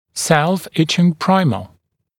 [ˌselfɪ’ɪʧɪŋ ‘praɪmə][ˌсэлфи’ичин ‘праймэ]самопротравливающий праймер